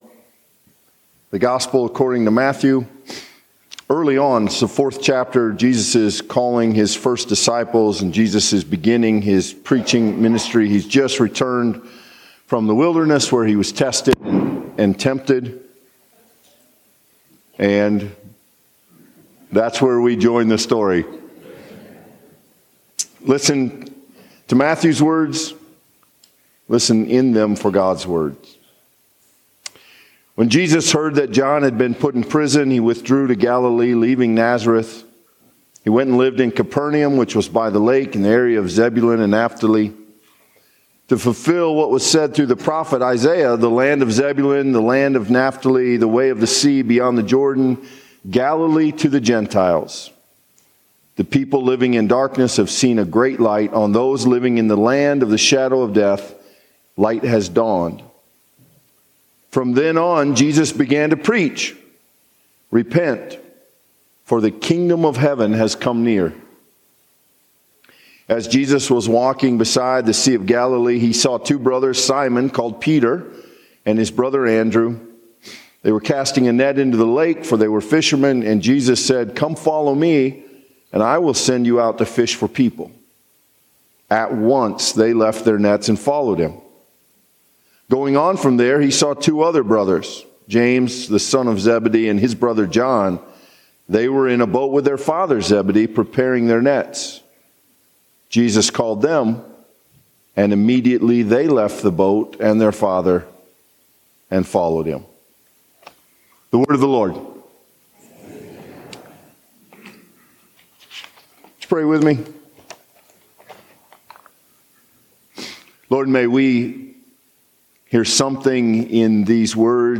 Sermons & Bulletins